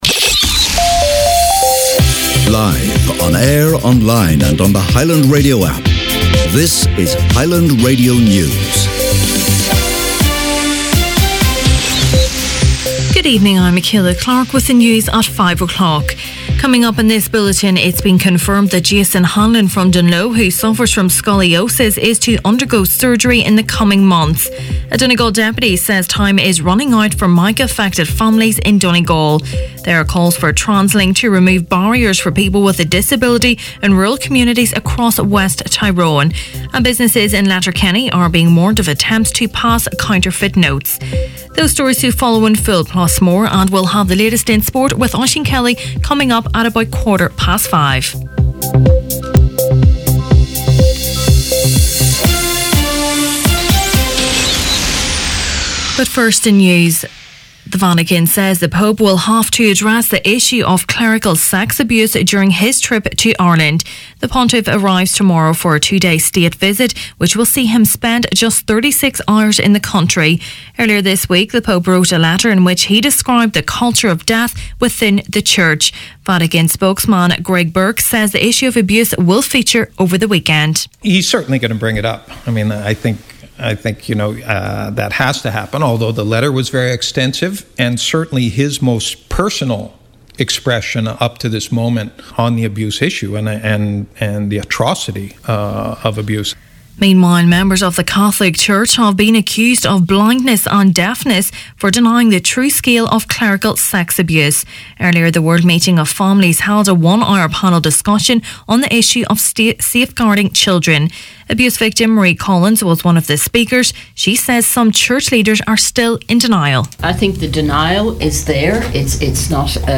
Main Evening News, Sport and Obituaries Friday August 24th